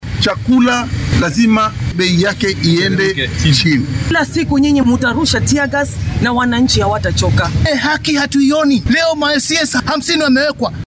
Hasa ahaate madaxdan oo warbaahinta la hadlay ayaa sheegay in dibadbaxyada ay socon doonaan xita haddii saraakiisha ammaanka ay howlgallada wadaan.
Siyaasiyiinta-mucaaradka-ee-la-xiray.mp3